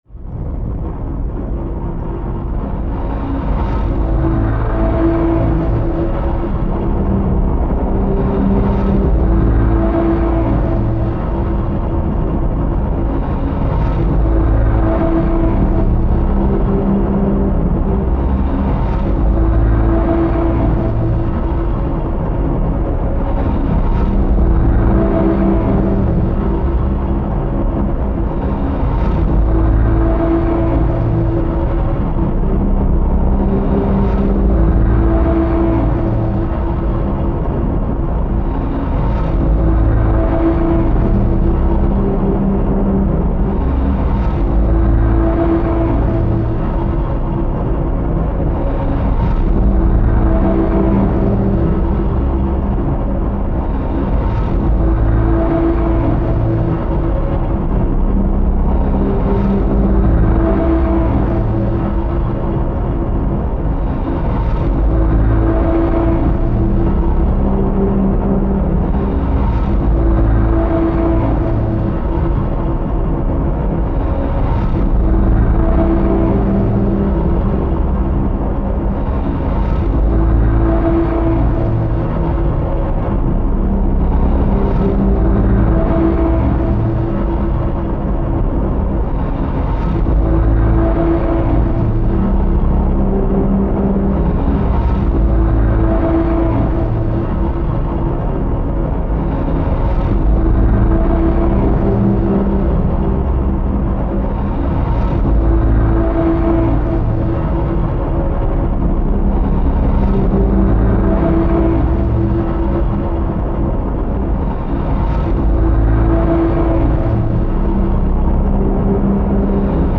dark ambient horror tension